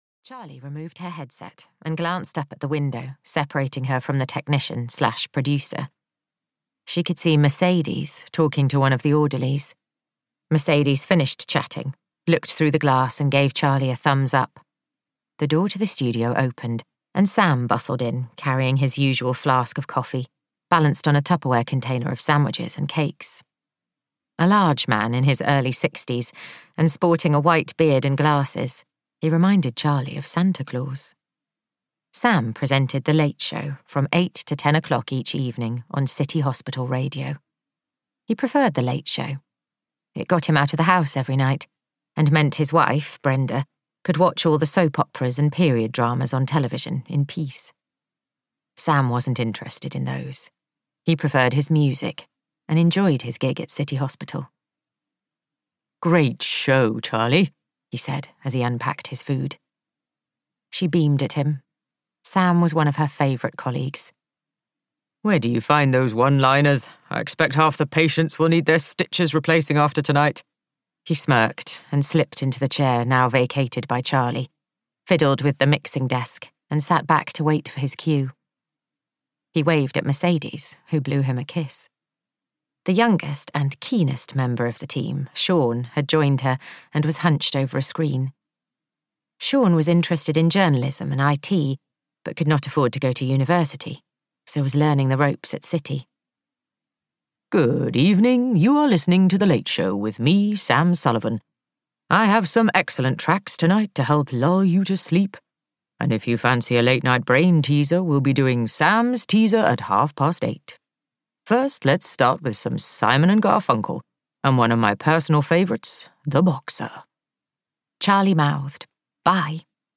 Take a Chance On Me (EN) audiokniha
Ukázka z knihy